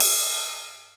• Long Ride Cymbal Sound Sample G Key 01.wav
Royality free ride sound tuned to the G note. Loudest frequency: 7583Hz
long-ride-cymbal-sound-sample-g-key-01-t54.wav